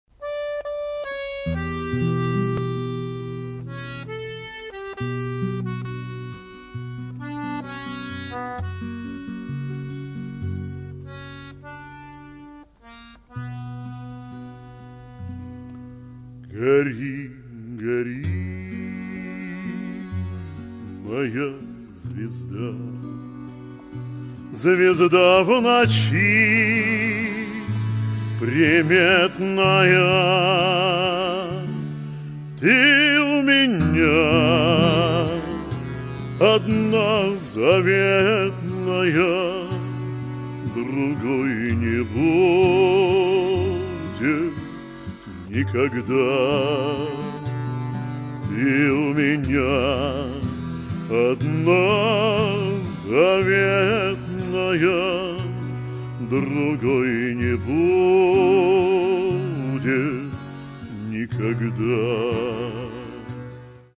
Романс Баян
Романс Гитара Баян
Работаем без микрофонов и аппаратуры (живая акустика).